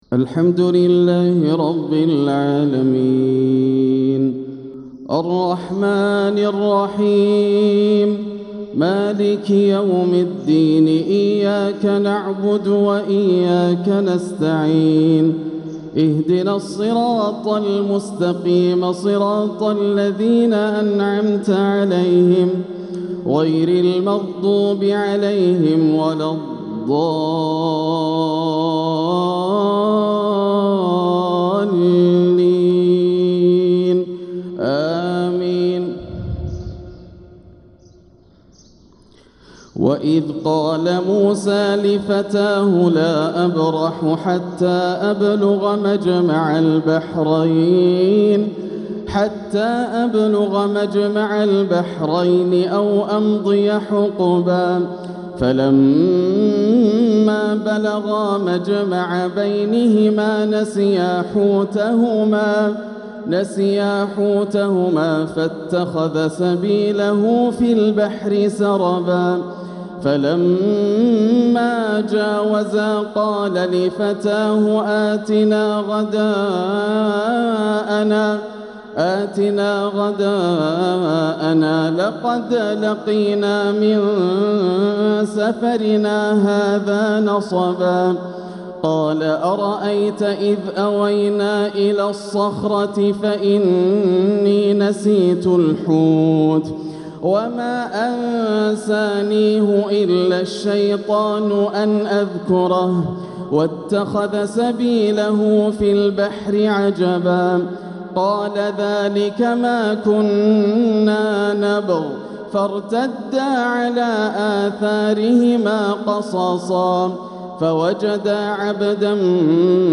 تلاوة مسترسلة جميلة من سورة الكهف | فجر ١٤ جمادى الأولى ١٤٤٦ هـ > عام 1446 > الفروض - تلاوات ياسر الدوسري